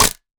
Minecraft Version Minecraft Version latest Latest Release | Latest Snapshot latest / assets / minecraft / sounds / mob / goat / horn_break4.ogg Compare With Compare With Latest Release | Latest Snapshot
horn_break4.ogg